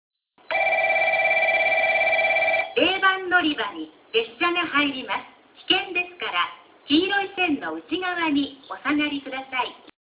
0番のりば接近放送　女声
▽は0番のりばの旧放送、旧携帯電話機材での収録です。